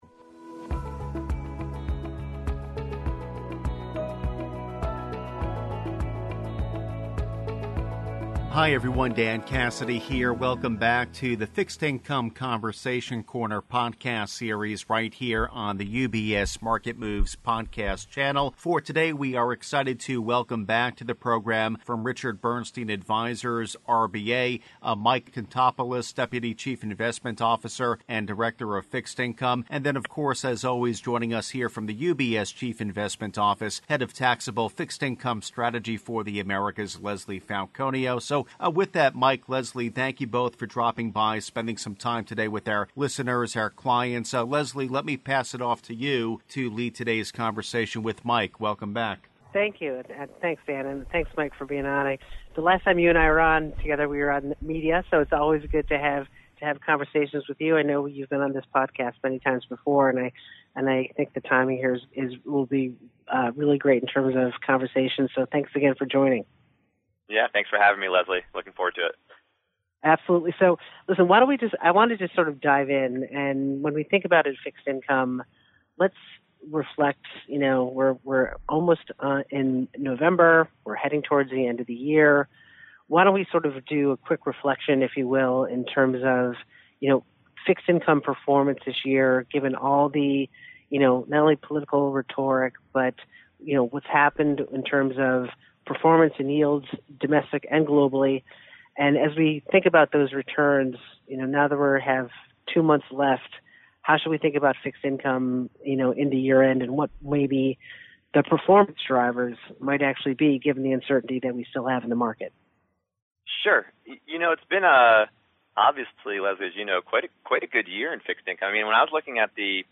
UBS On-Air: Market Moves Fixed Income Conversation Corner Podcast